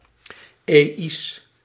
Ääntäminen
IPA: /ɛi̯s/